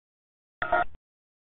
Identify Dialers By Call Answer Sound